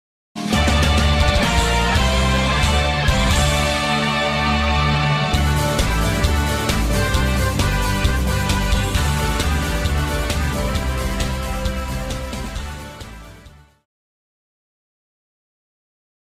Epic Win Sound
cartoon